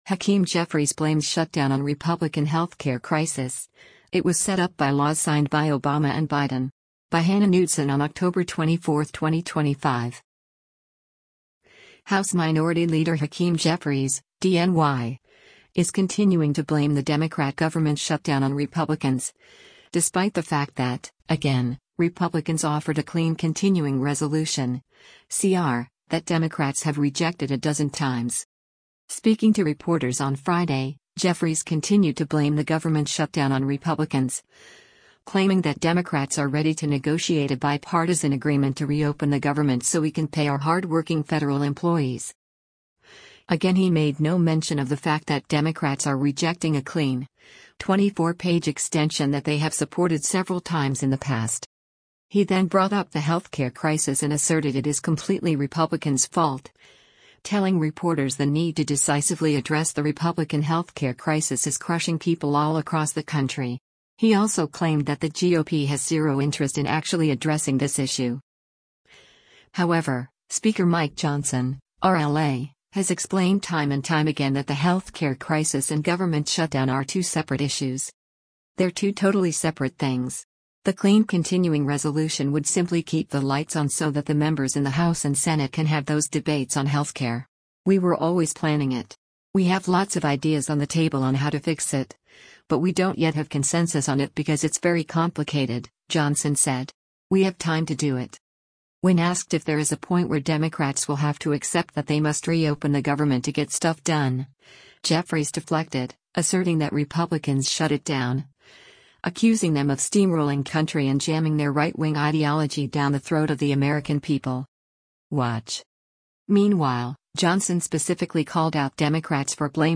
Representative Hakeem Jeffries, a Democrat from New York, during a news conference at the
Speaking to reporters on Friday, Jeffries continued to blame the government shutdown on Republicans, claiming that Democrats are ready to “negotiate a bipartisan agreement to reopen the government so we can pay our hardworking federal employees.”